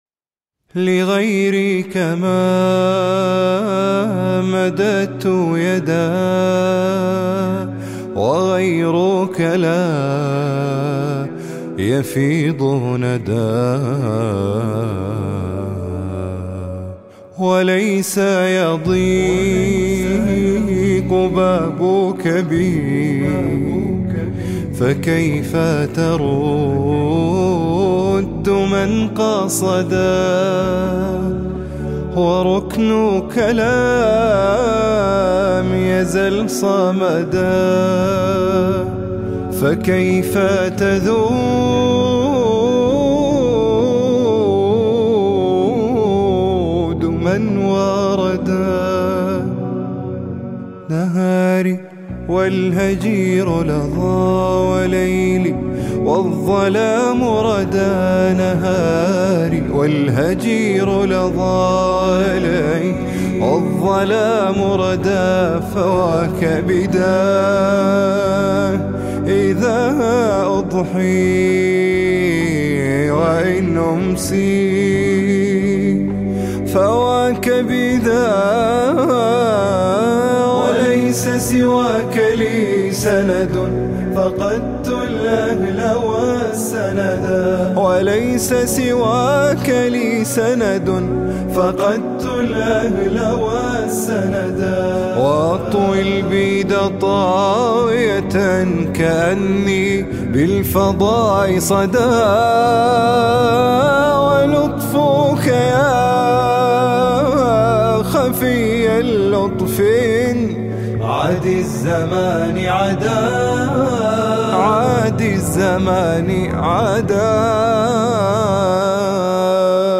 نشید يا خَفيَّ اللُّطفِ: